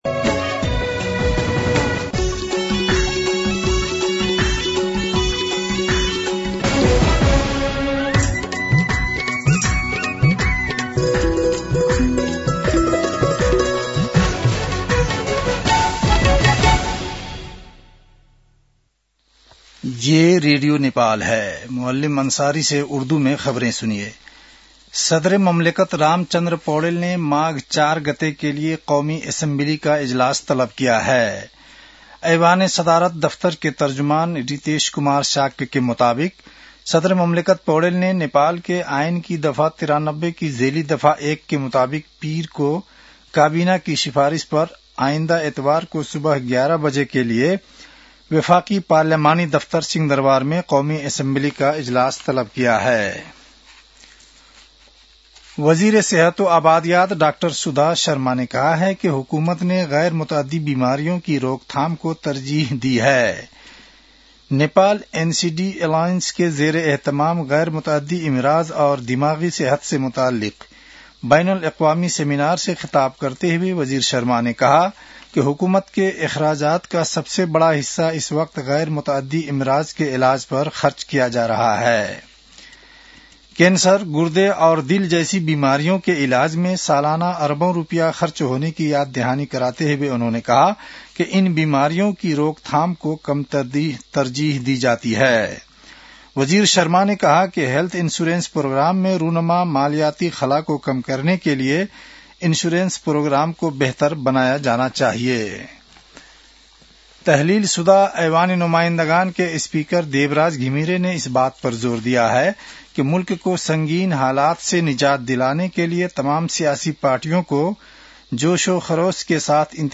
उर्दु भाषामा समाचार : २९ पुष , २०८२